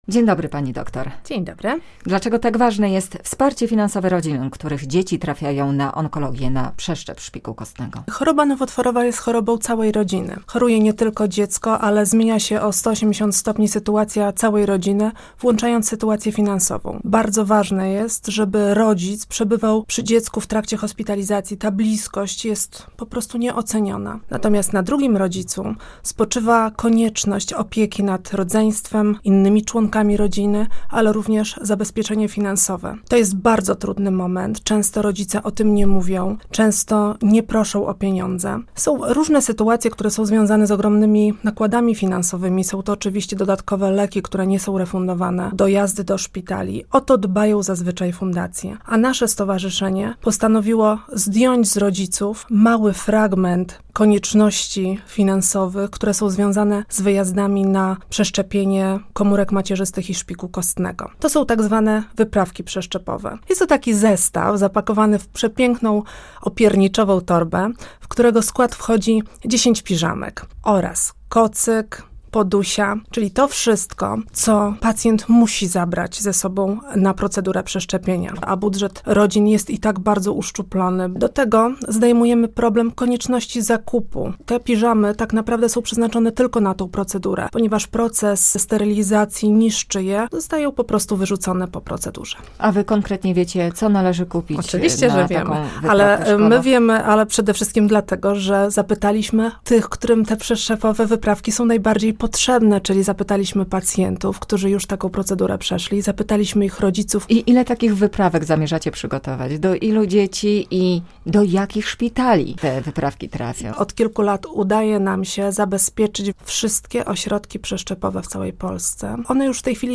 GOŚĆ RADIA BIAŁYSTOK